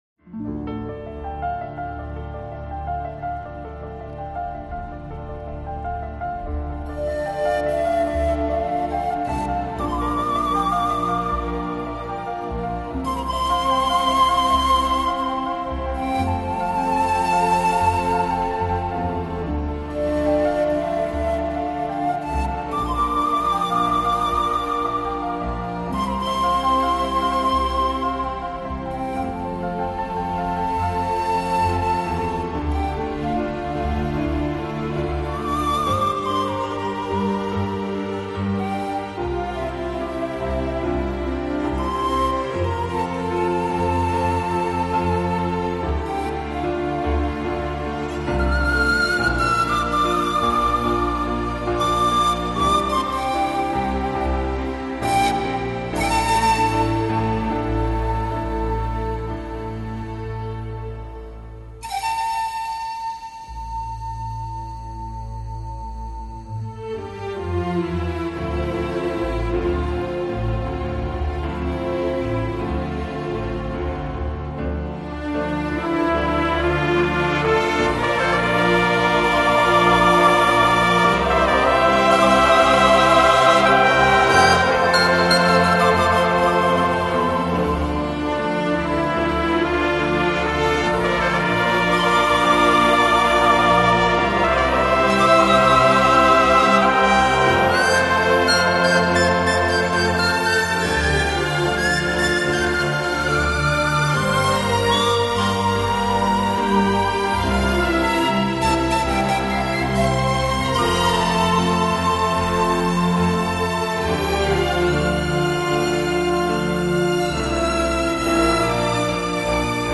FLAC Жанр: Classical, Instrumental Издание